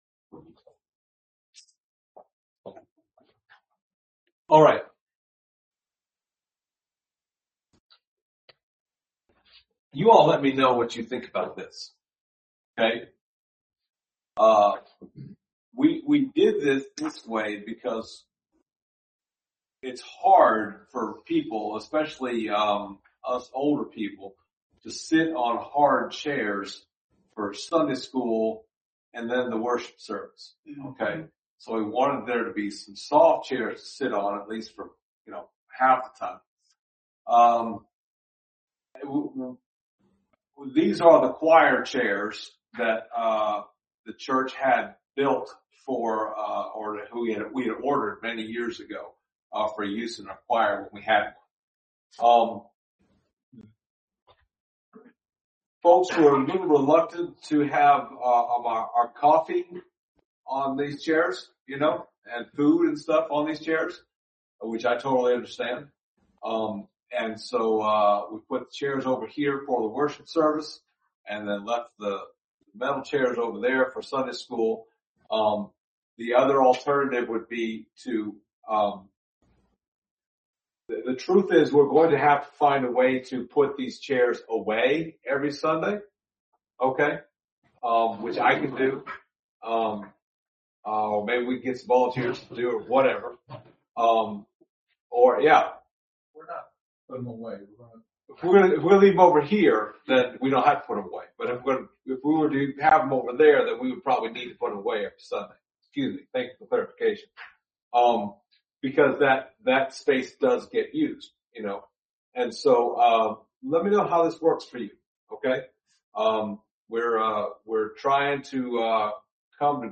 The Acts of the Holy Spirit Passage: Acts 15:1-12 Service Type: Sunday Morning « Grace or Law?